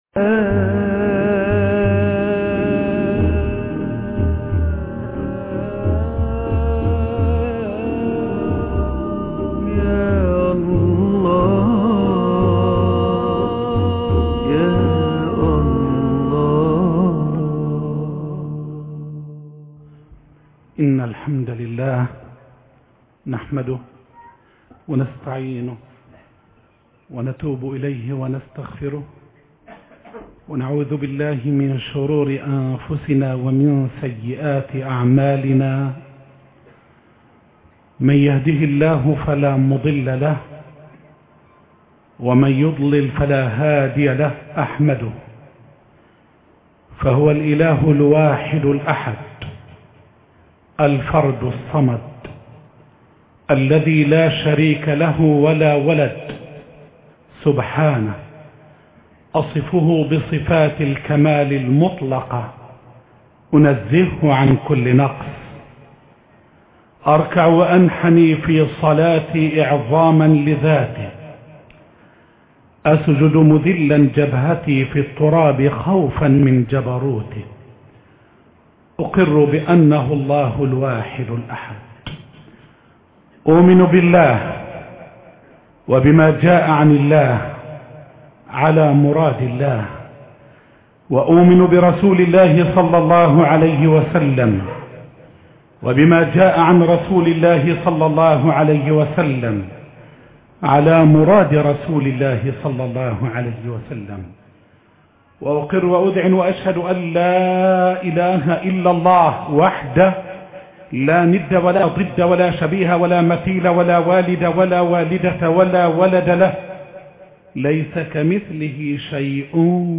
المنبر